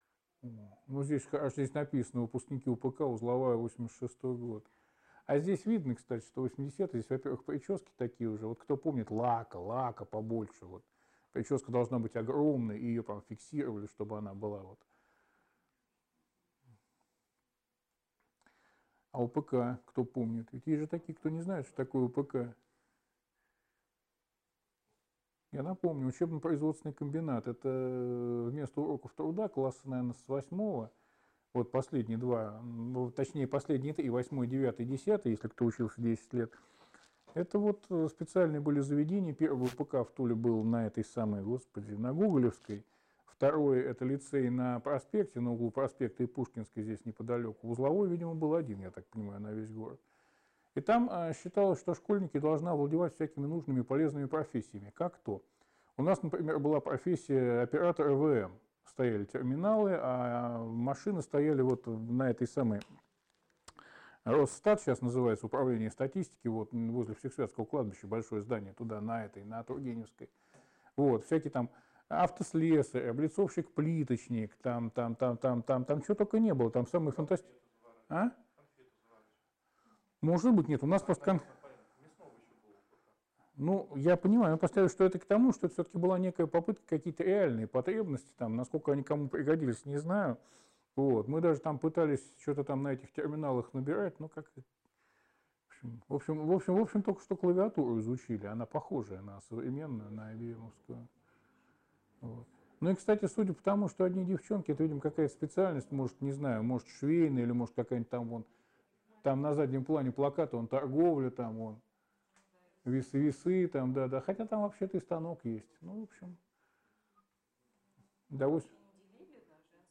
3. «Сеансы связи» в Доме Белявского